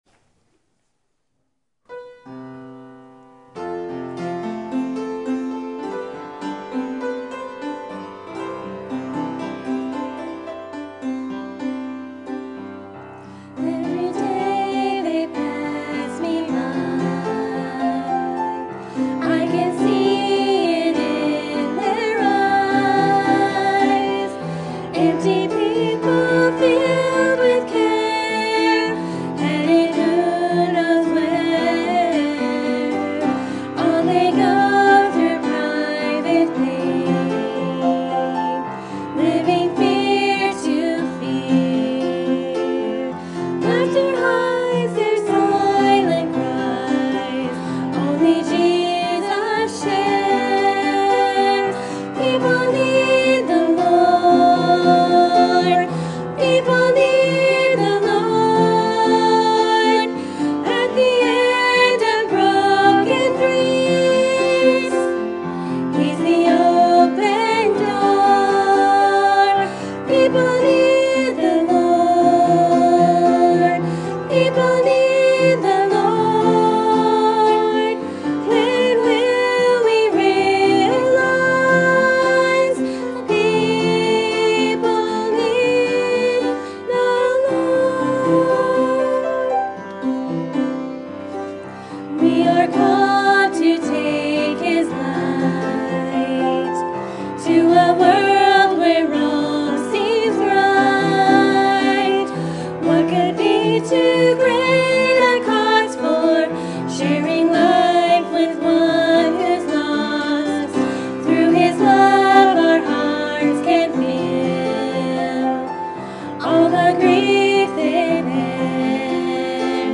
Sermon Topic: General Sermon Type: Service Sermon Audio: Sermon download: Download (21.63 MB) Sermon Tags: John Nicodemus Salvation